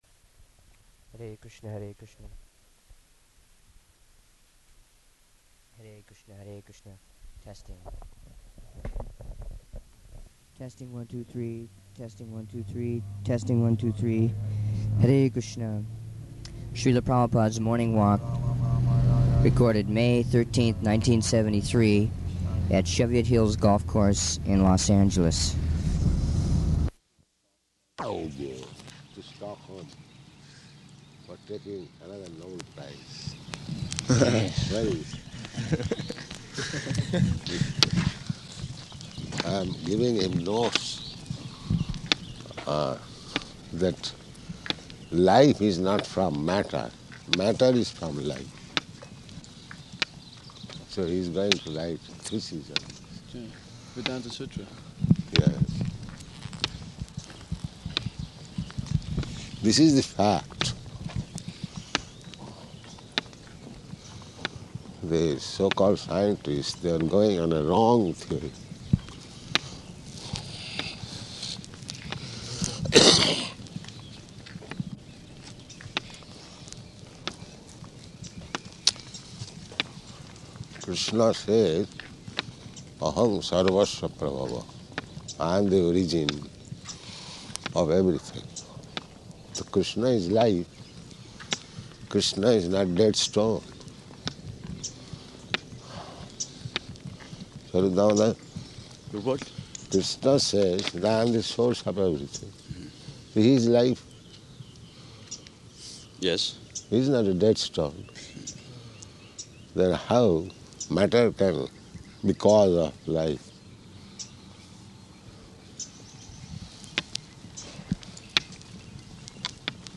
Morning Walk at Cheviot Hills Golf Course
-- Type: Walk Dated: May 13th 1973 Location: Los Angeles Audio file